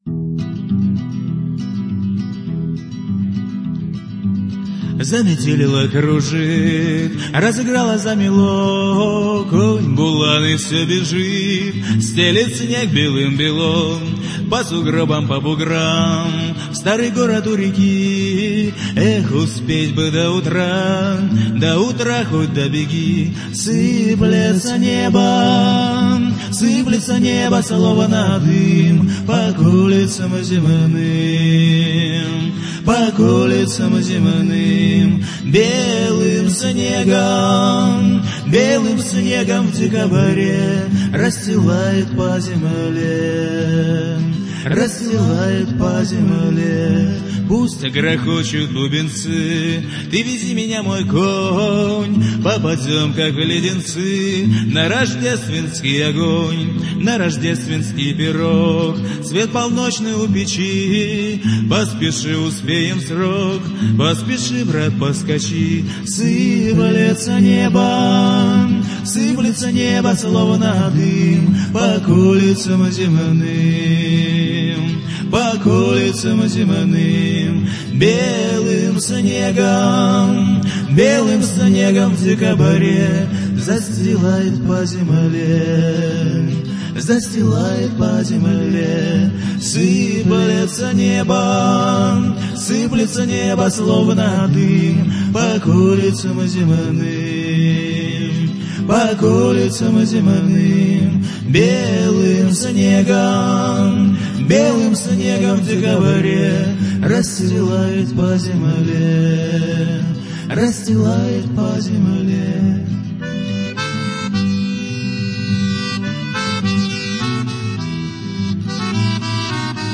Авторская песня (28133)